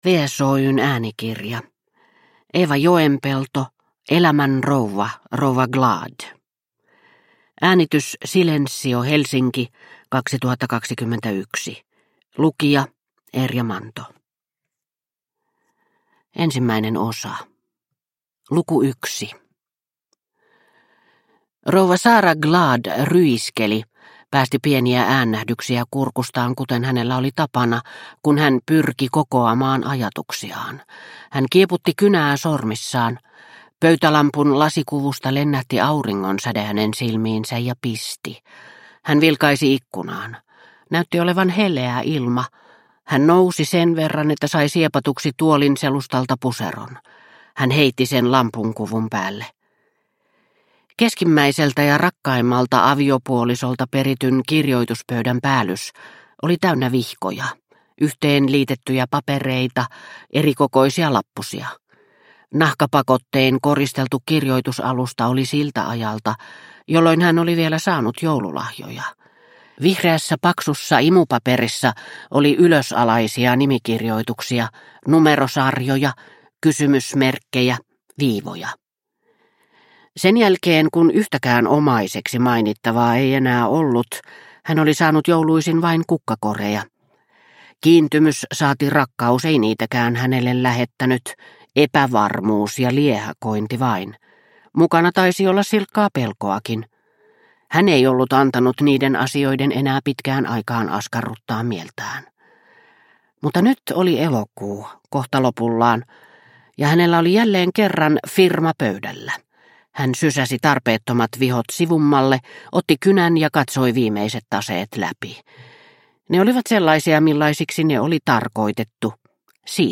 Elämän rouva, rouva Glad – Ljudbok – Laddas ner